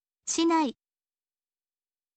shinai